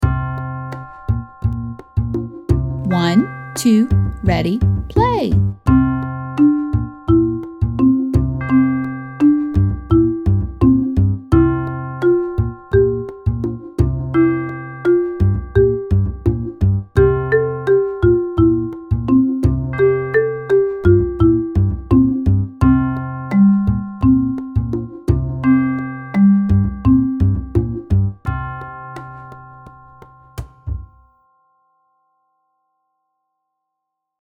SLOW TEMPO WITH INTRO